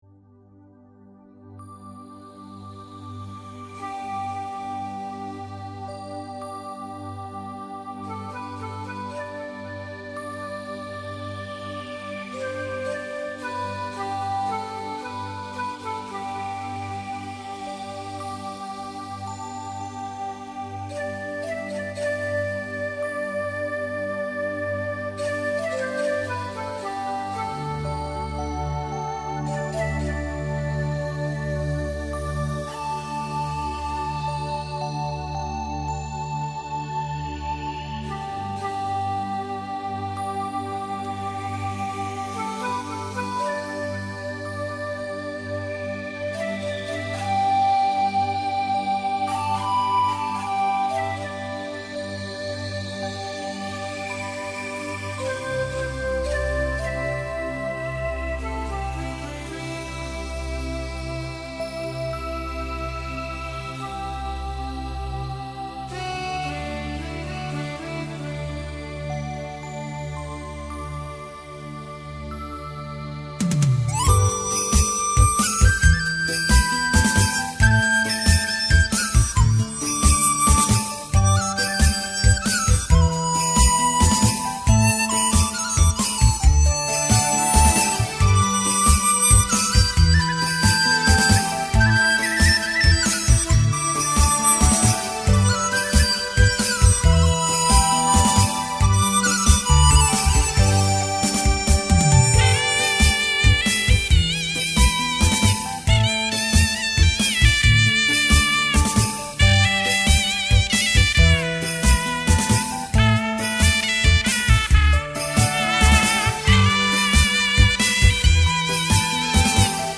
中华民乐精英荟萃
笛子 唢呐